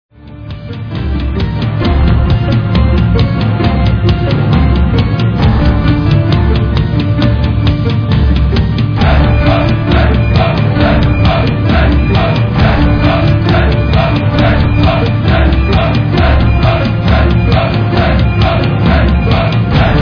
'dark neo-classical/industrial'